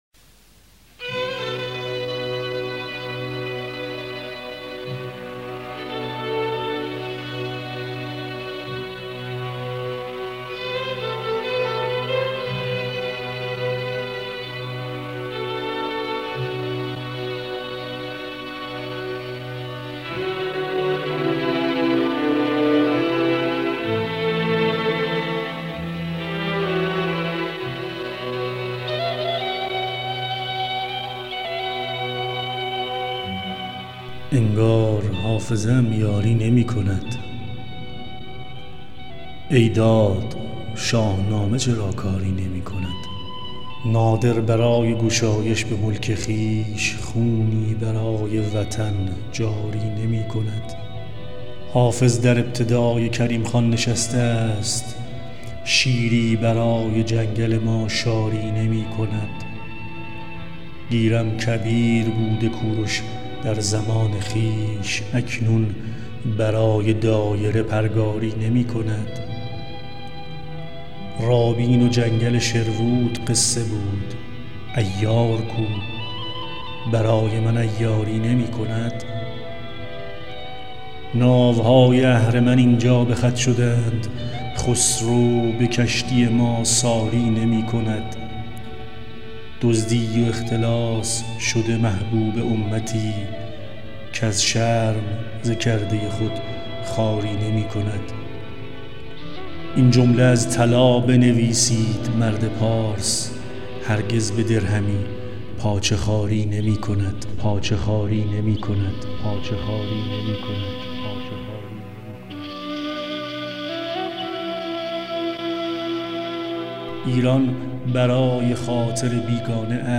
این شعر هم از مثلا اشعار قدیمیمه که صوتیش کردم براتون